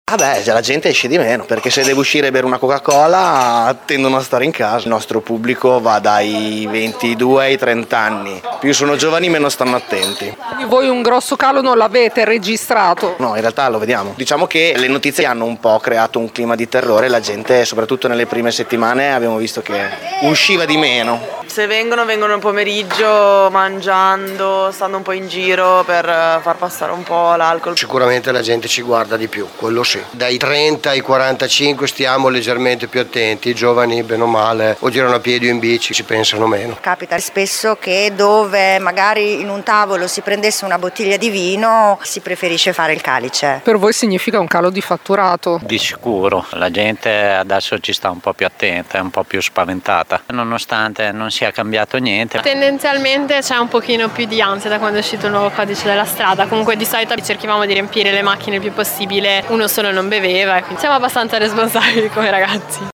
Vox Populi 0
Il parere di alcuni baristi e titolari di locali in centro…